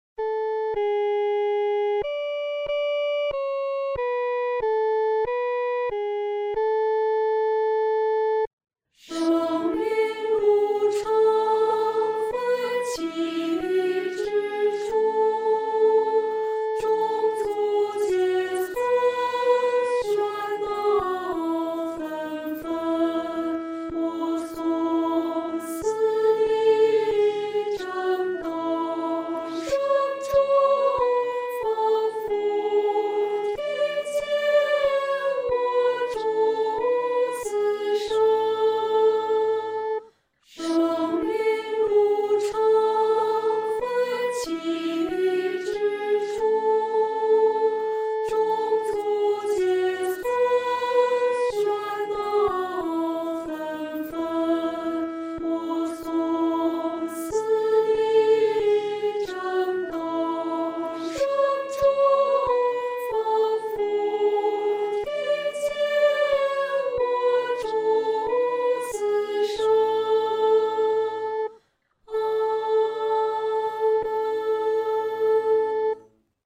合唱
女高
这首圣诗适用中速弹唱